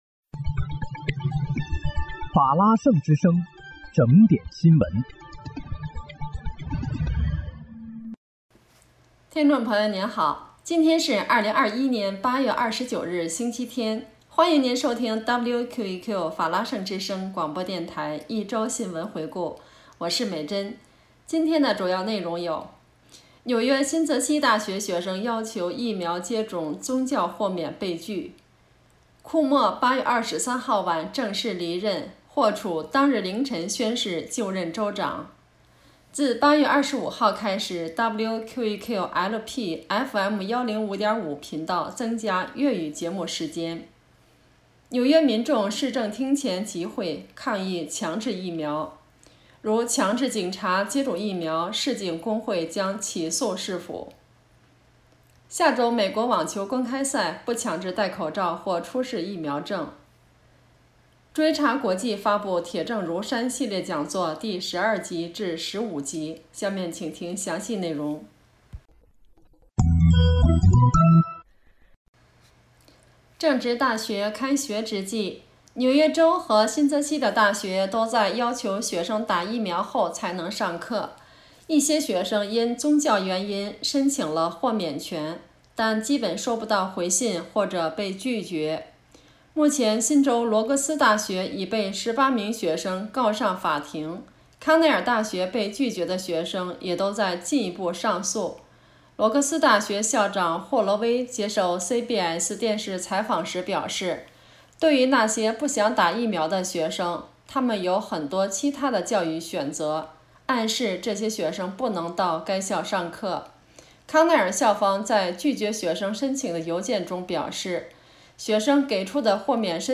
8月29日（星期日）一周新闻回顾